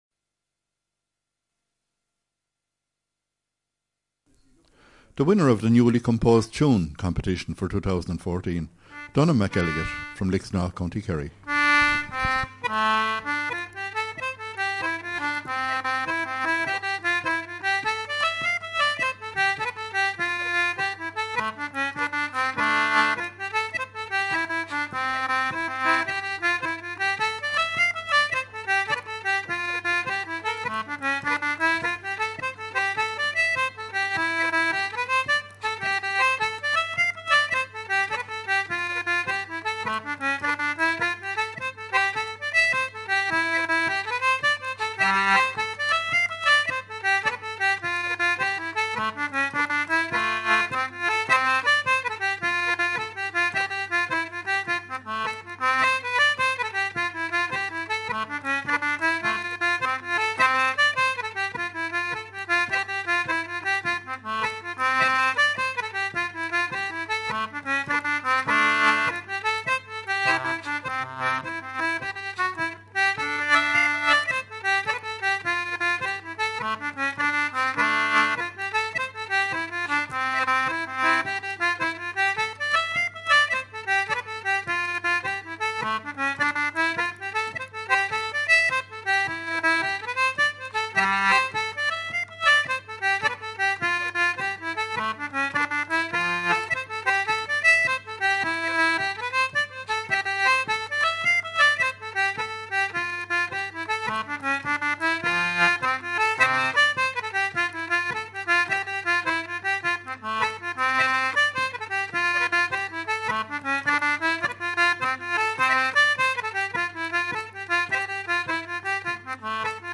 jig
Samphire Island” played on concertina
at a session at Fleadh Cheoil na hÉireann 2014 in Sligo